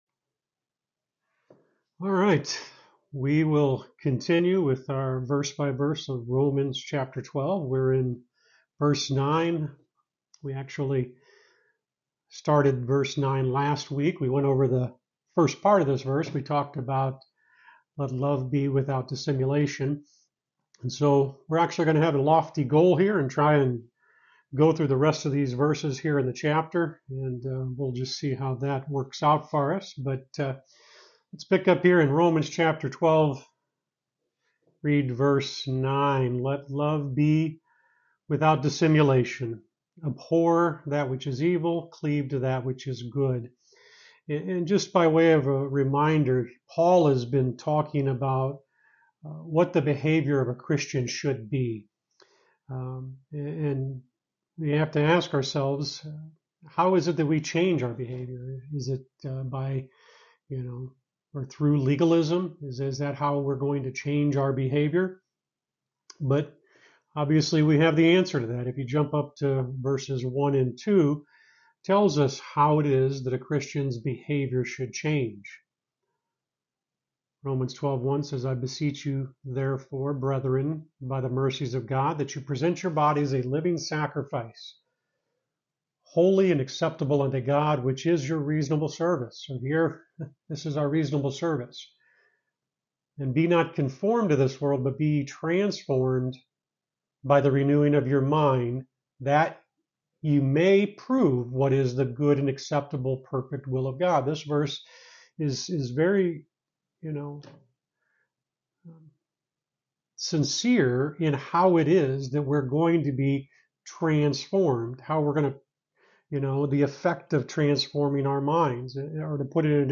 You're listening to Lesson 79 from the sermon series "Romans